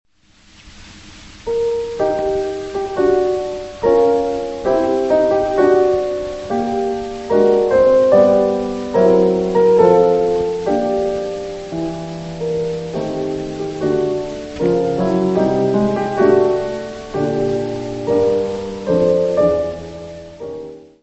piano.
Área:  Música Clássica